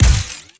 GUNTech_Sci Fi Shotgun Fire_01_SFRMS_SCIWPNS.wav